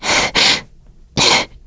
Isolet-Vox_Sad.wav